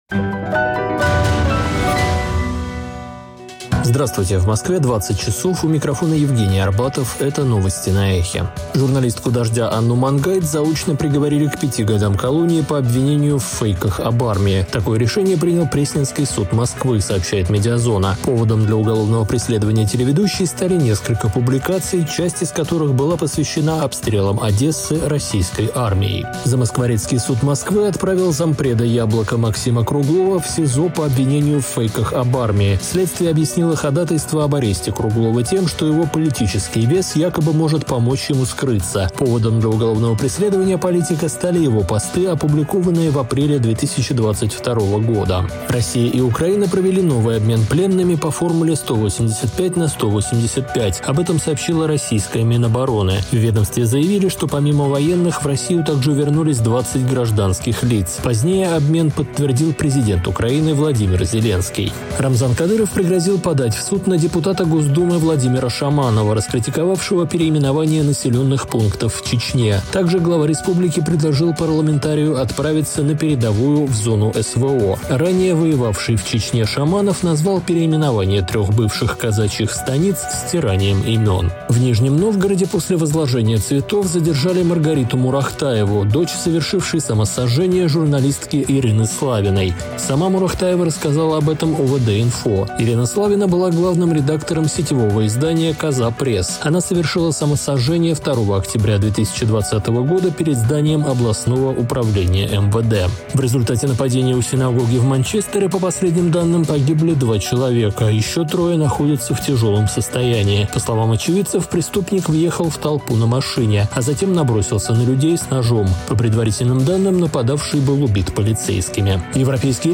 Слушайте свежий выпуск новостей «Эха»